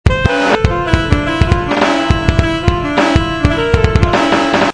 Alto SAXにはディレイとリバーブを掛けてあります。
先ほどのリズムにAlto SAXを追加したフレーズ・サンプル（MP3）